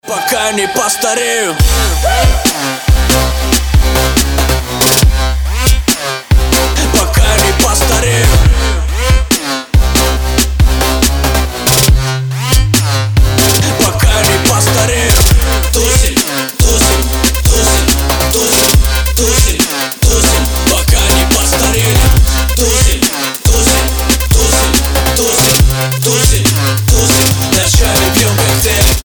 громкие
Хип-хоп